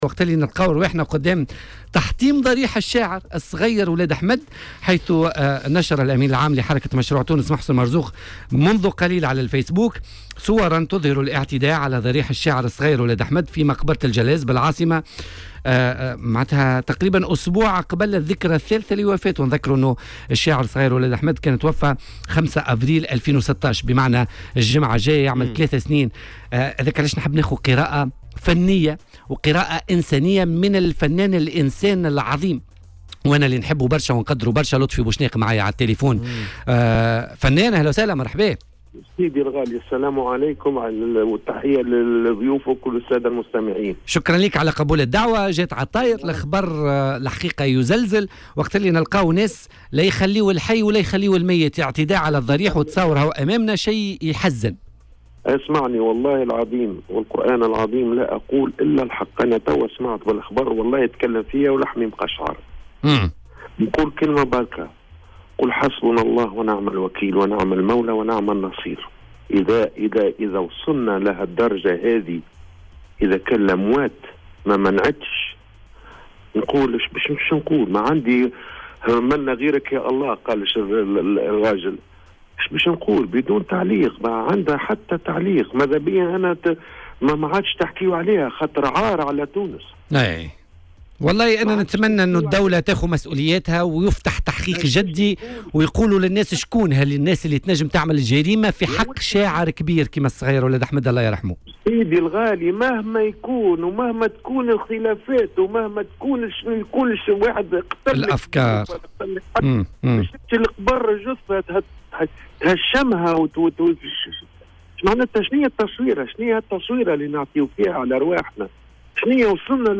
وقال في مداخلة هاتفية مع "بوليتيكا" على "الجوهرة أف أم" إنه يعجز على التعليق ووصف ما حصل بـ "العار على تونس"، وفق تعبيره.